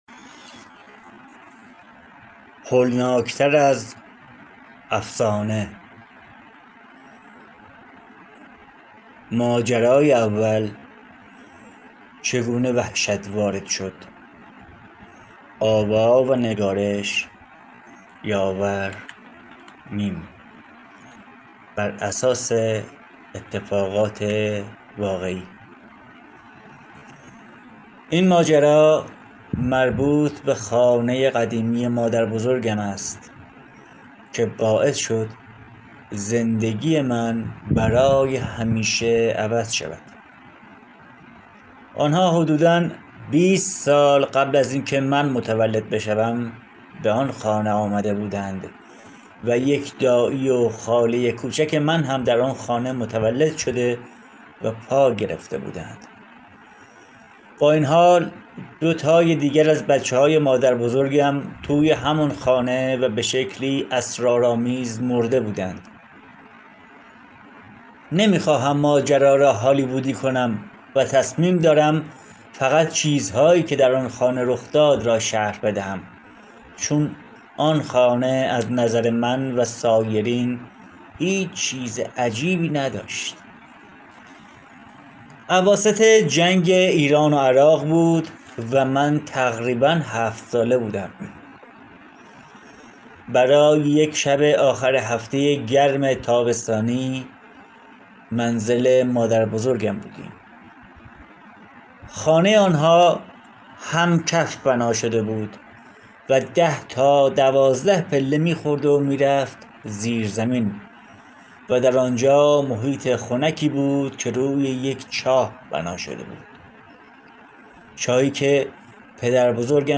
کتاب صوتی